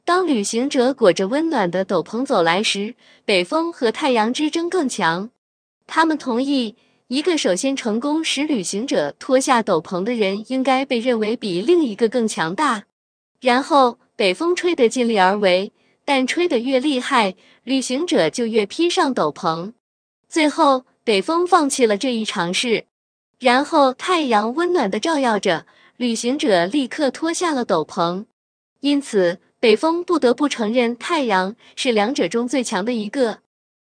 尝试过Coqui TTS吗？开源免费的，下面链接里有很多样例可以试听：